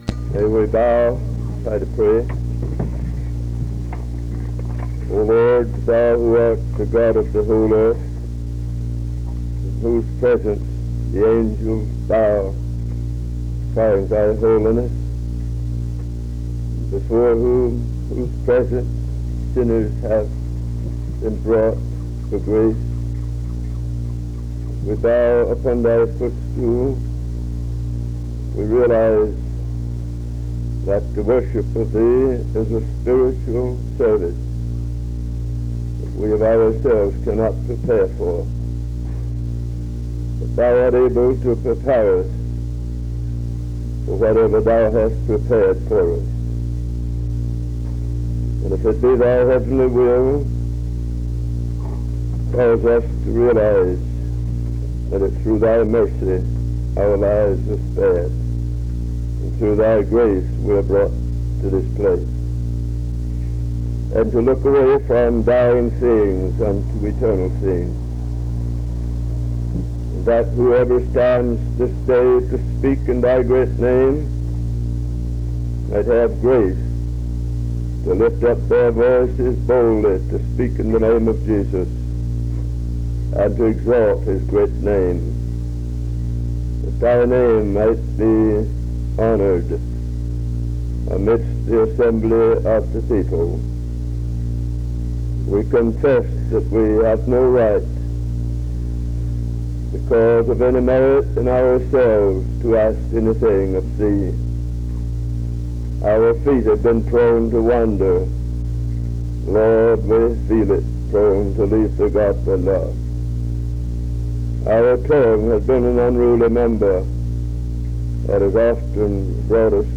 Download .mp3 Description Sermons and singing from the 1965 Smith River Association Meeting, held at Salem Church, also known as Head of the River Church; This work is compiled from several reels of differing collections of sermons from this association meeting and includes duplicates of certain sermons.
Primitive Baptists